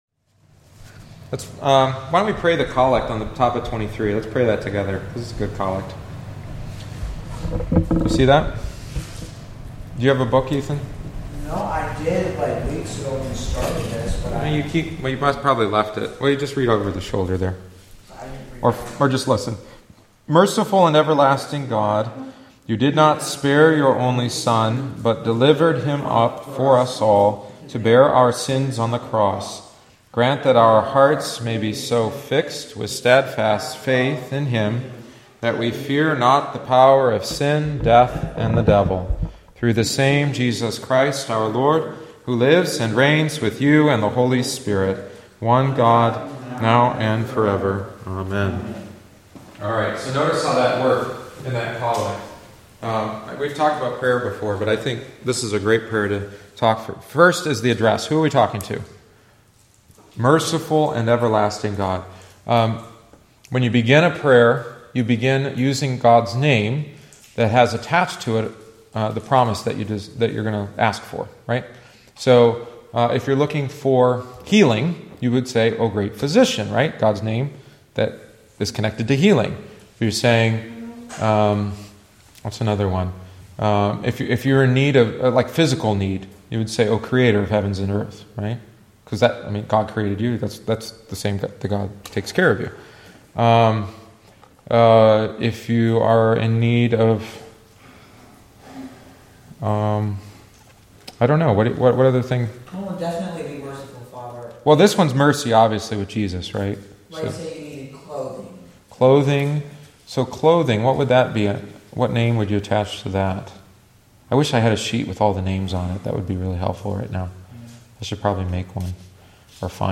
Join us for Adult Catechumenate classes following each Wednesday Divine Service.